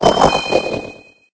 Cri de Wimessir mâle dans Pokémon Épée et Bouclier.